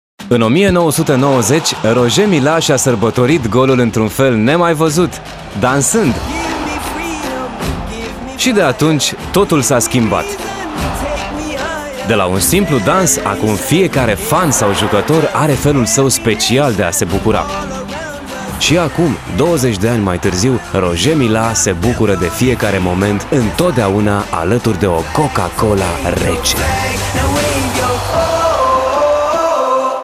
Male
Teenager (13-17), Adult (30-50)
Dynamic, enthousiastic, with a fresh and friendly sound.
Television Spots